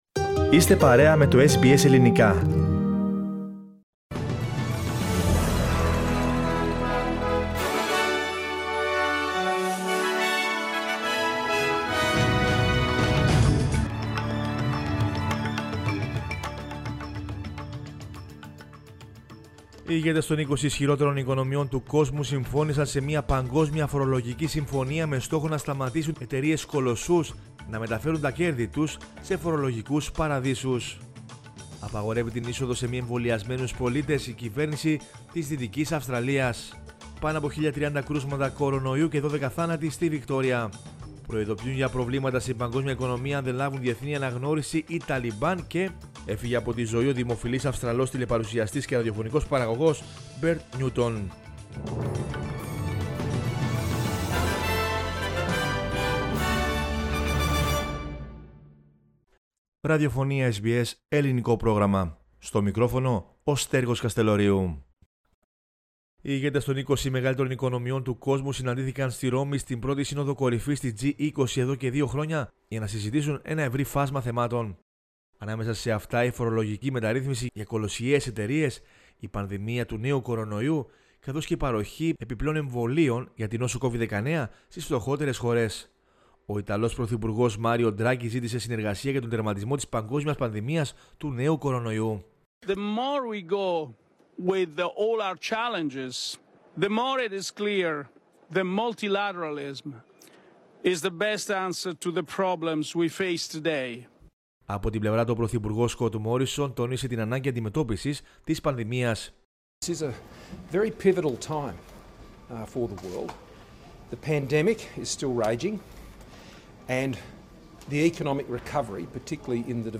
News in Greek from Australia, Greece, Cyprus and the world is the news bulletin of Sunday 31 October 2021.